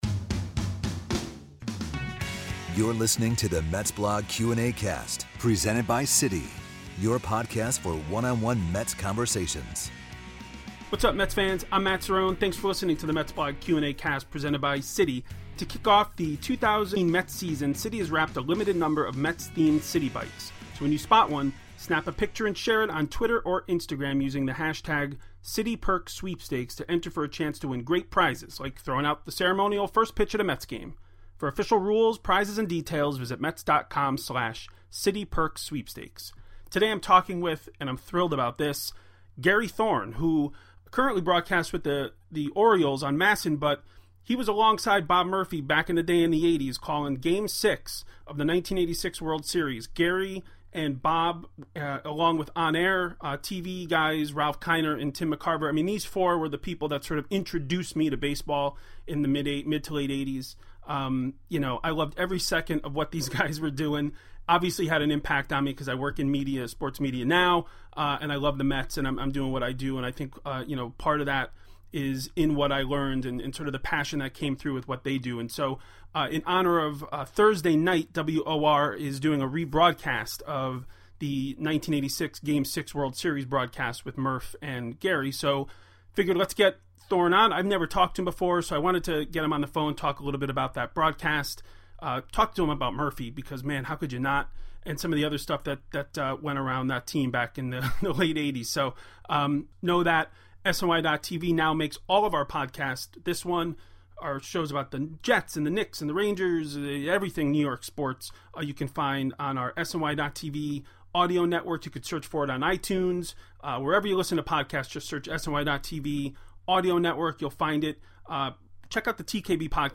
MetsBlog Q&Acast: Gary Thorne interview